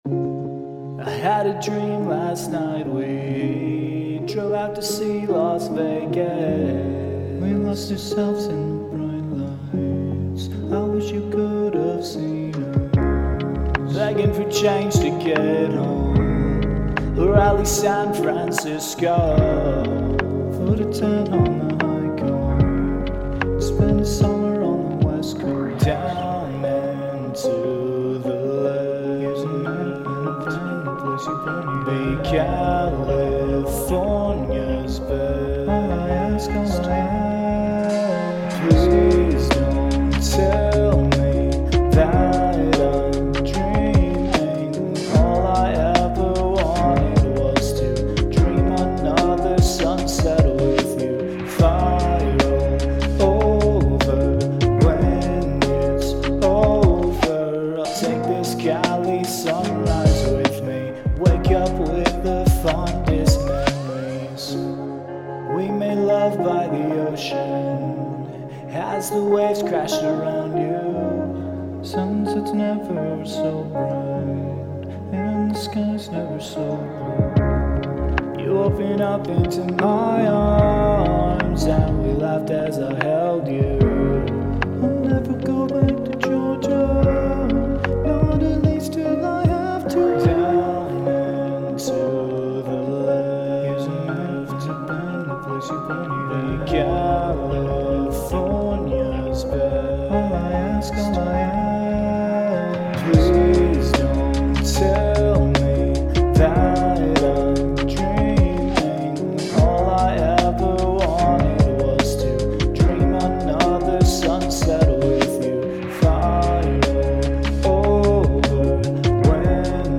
VOCAL COVER!!